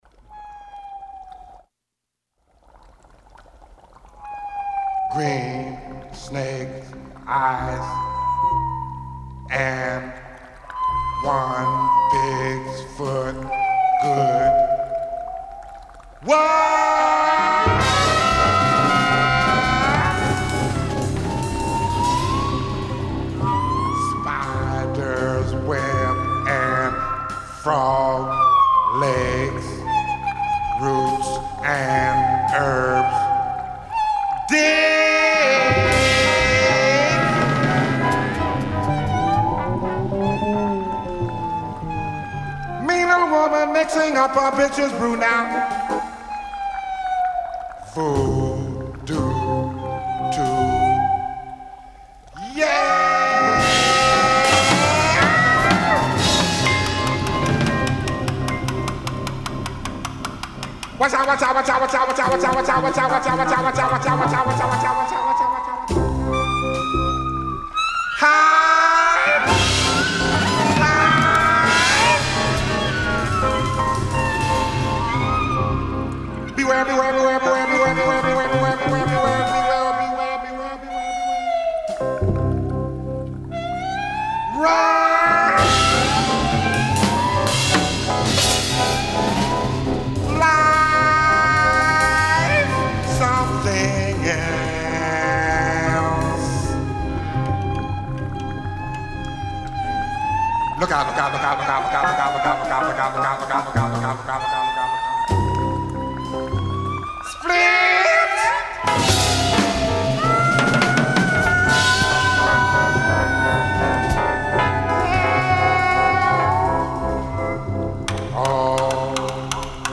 Avant-Garde Funk/Soul Hip Hop Jazz